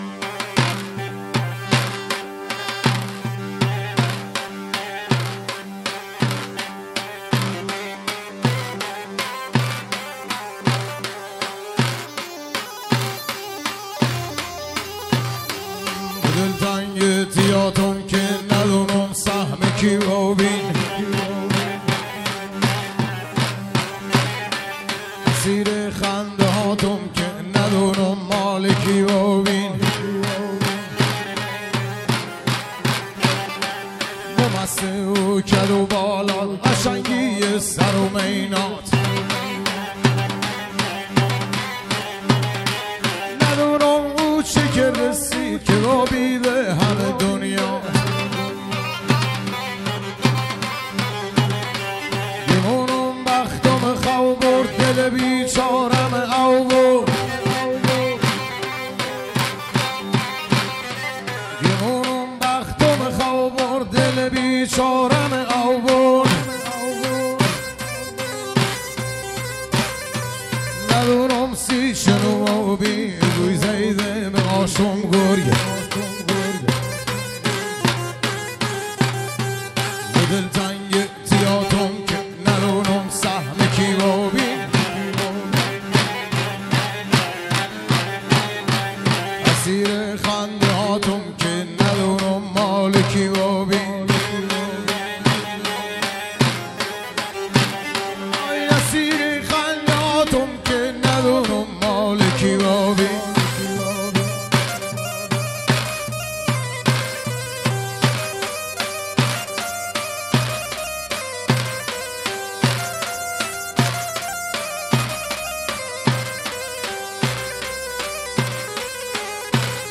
محلی لری عروسی